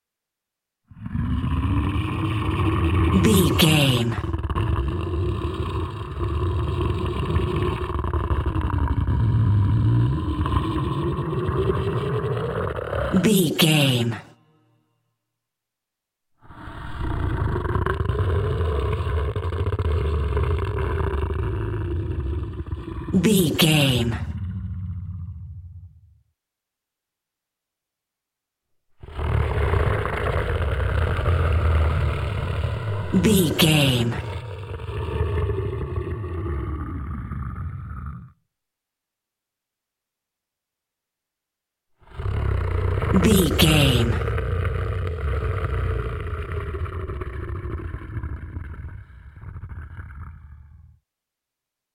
Monster sleep snore big creature x4
Sound Effects
ominous
eerie